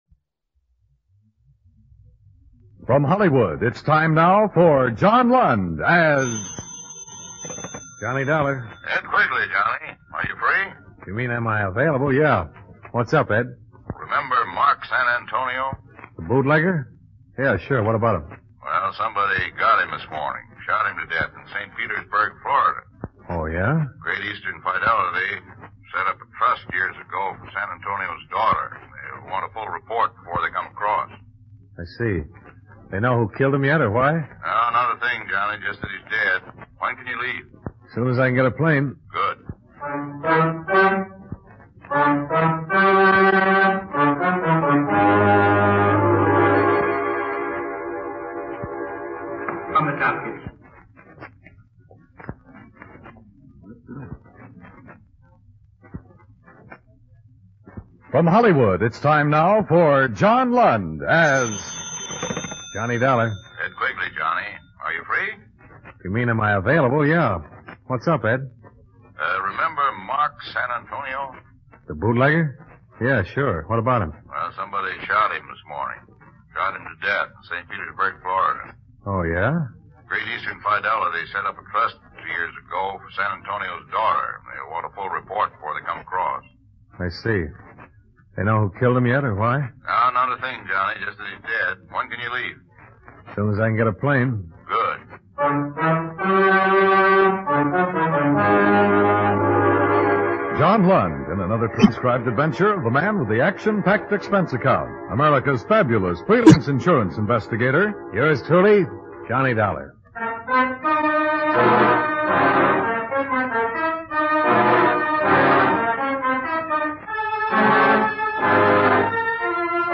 The San Antonio Matter (rehearsal)
1953-04-28-The-San-Antonio-Matter-Rehearsal.mp3